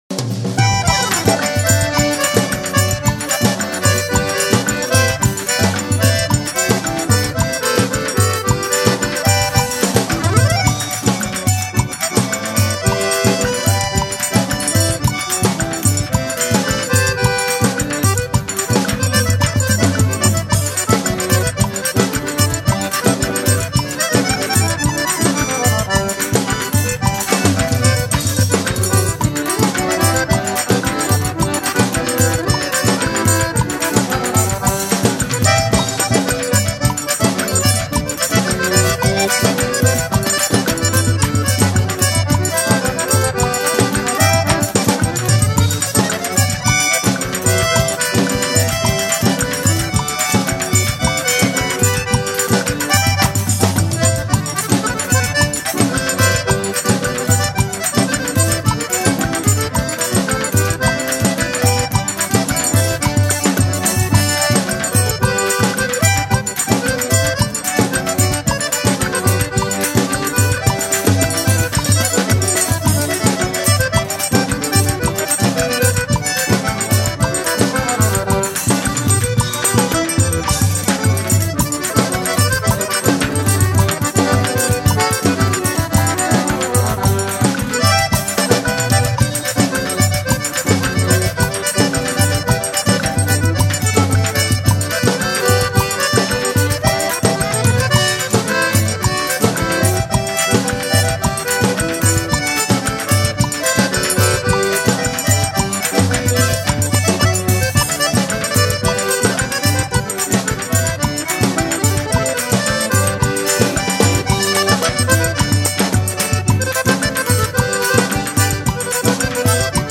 Sanfona